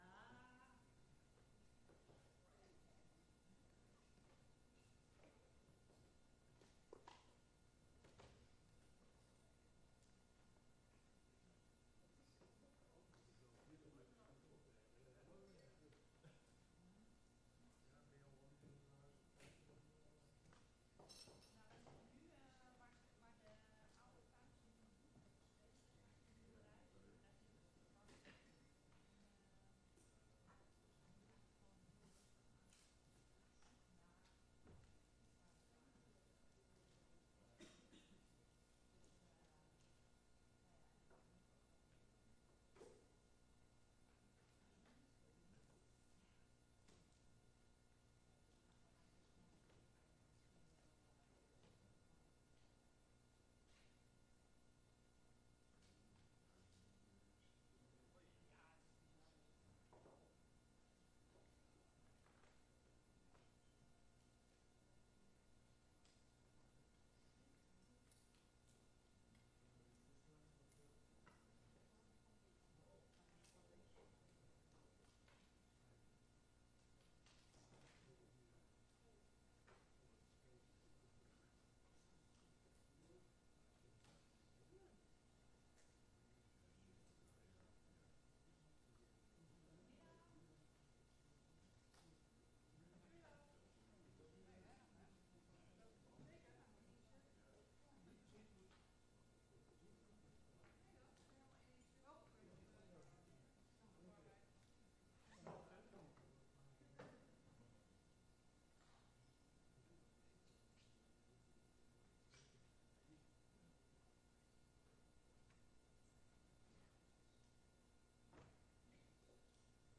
Locatie: Statenzaal